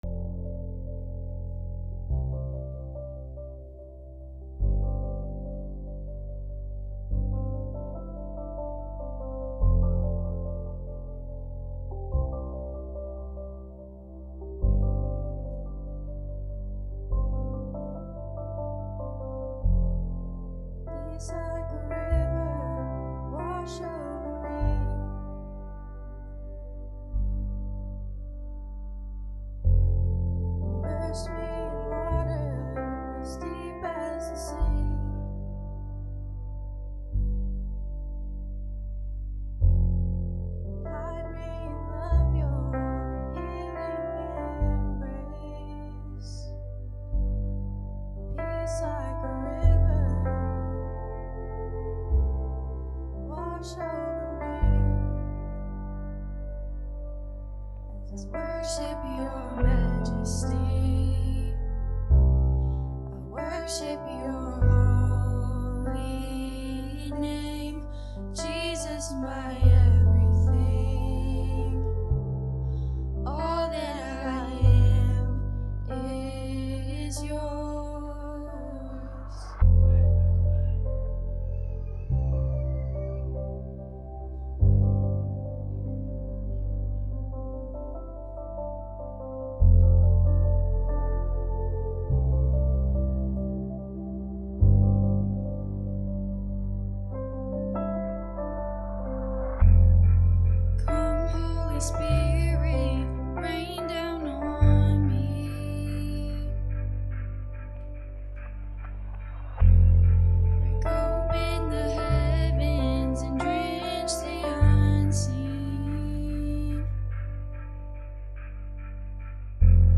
Sermons | Richardson's Cove Baptist Church